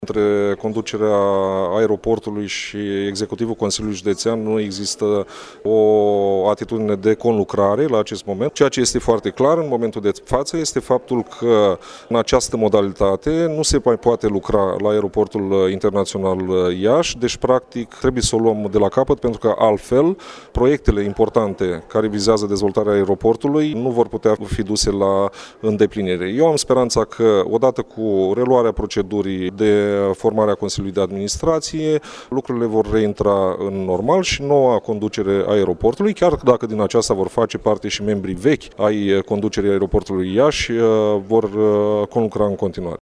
În replică, preşedintele grupului de consilieri social democraţi din Consiliul Judeţean Iaşi, Cristi Stanciu, a precizat că în ultima jumătate de an nu a existat o colaborare cu  managerul interimar de la Aeroport şi în această situaţie proiectele nu pot fi duse la îndeplinire: